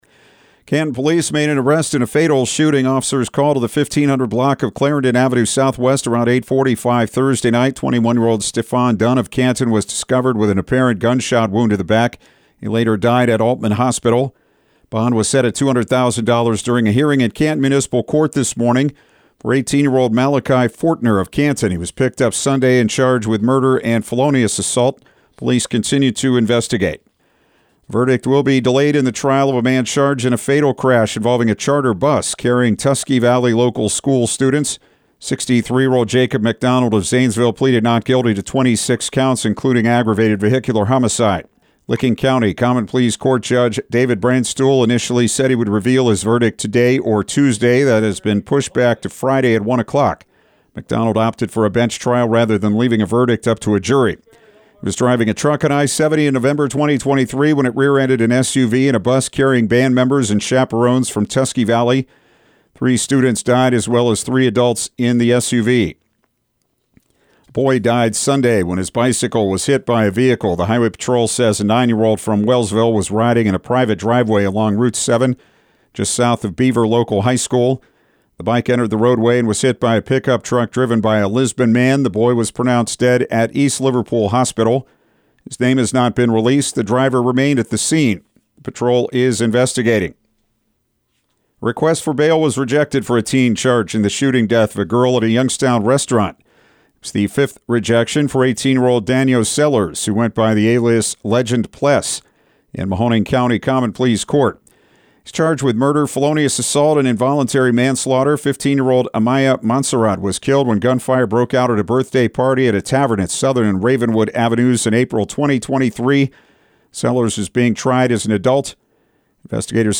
Afternoon News